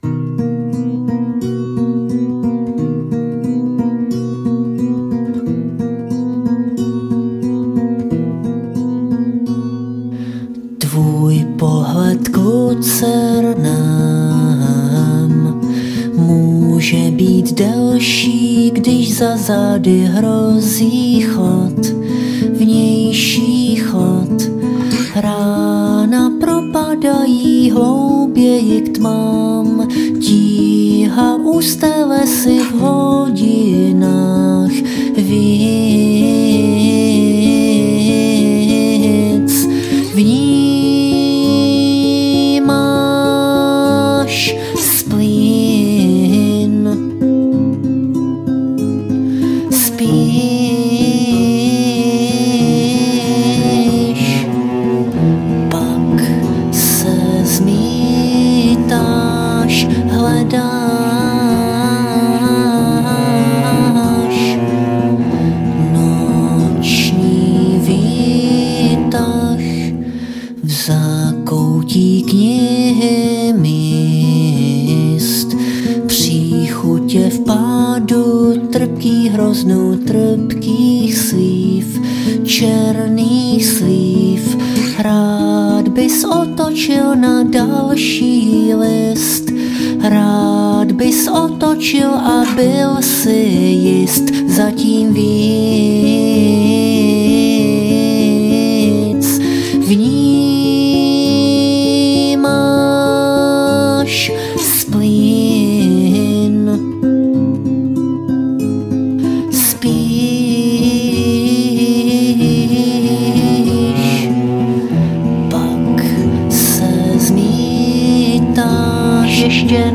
Žánr: Indie/Alternativa
Sedmé studiové album bizarního písničkáře z Kladna.